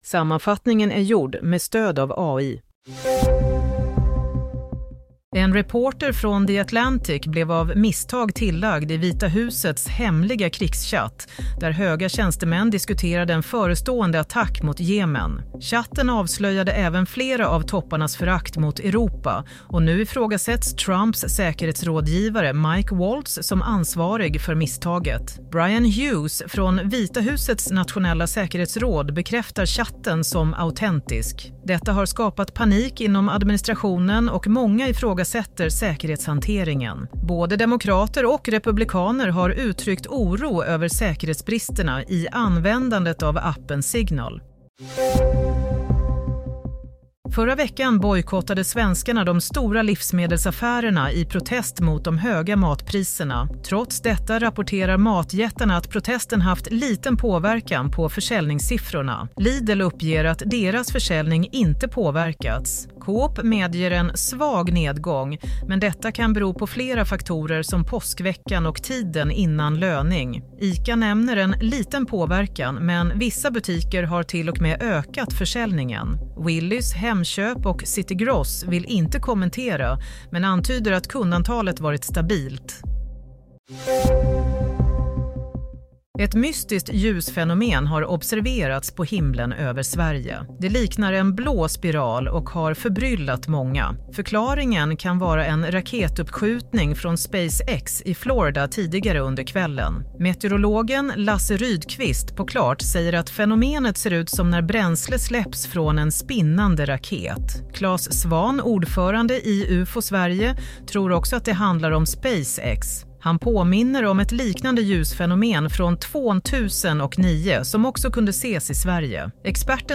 Nyhetssammanfattning - 25 mars 07:00